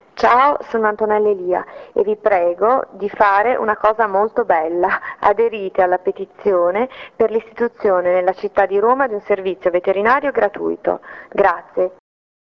ASCOLTA GLI SPOT DI ANTONELLA ELIA